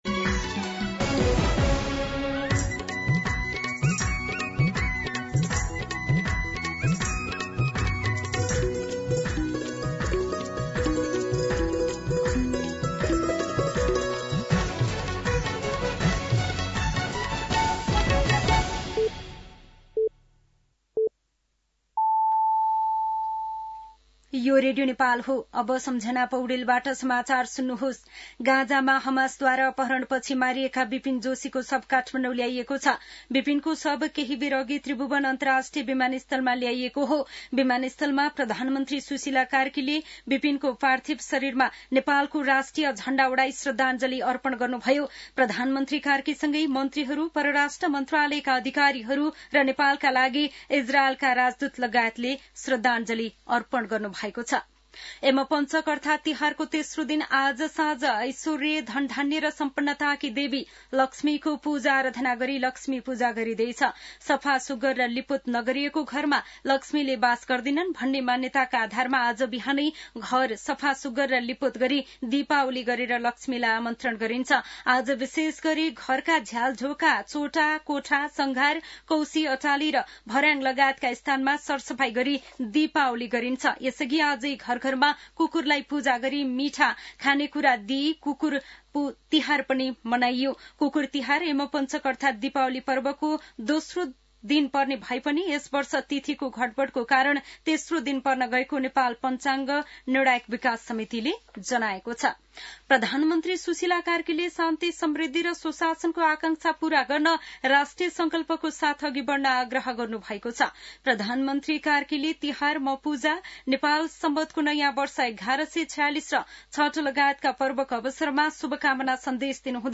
दिउँसो ४ बजेको नेपाली समाचार : ३ कार्तिक , २०८२
4-pm-Nepalii-News.mp3